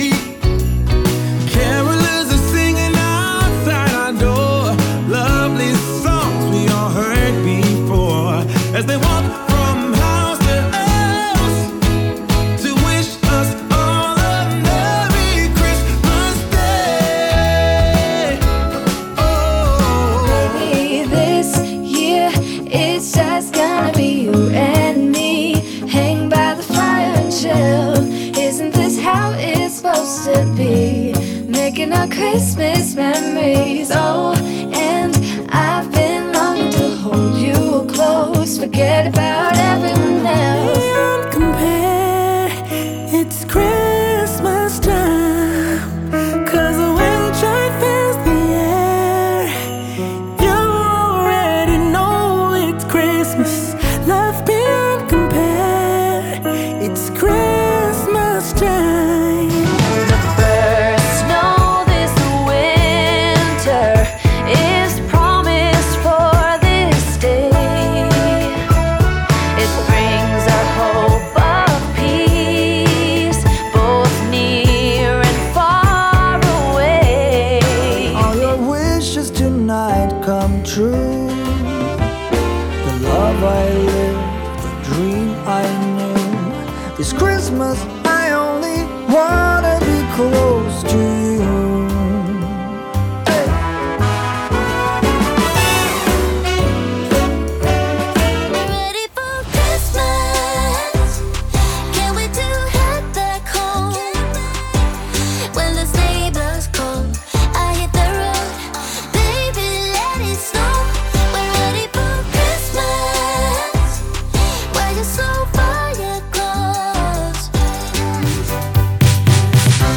Versatile mix
with various tempos
XMAS
All-Around Holiday Vibes